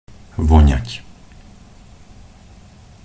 pronunciation_sk_vonat.mp3